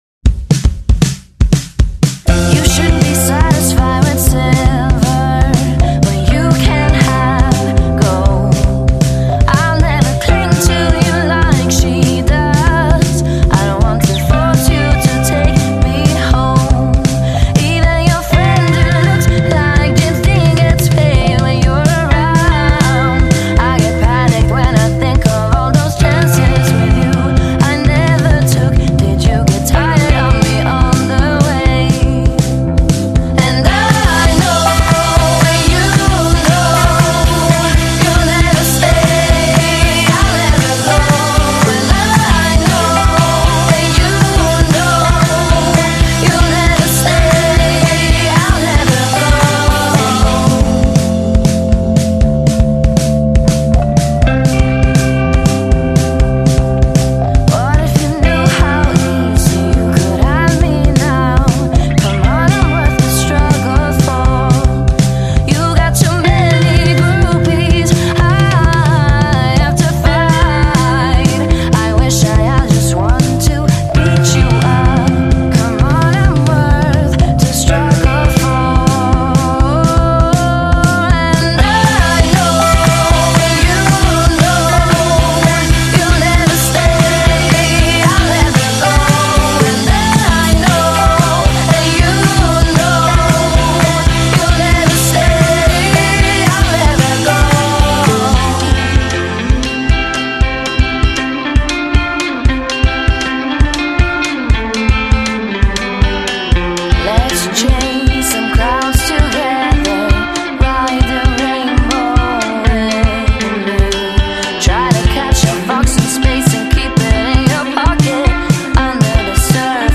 cocktail di indiepop, northern soul e spensieratezza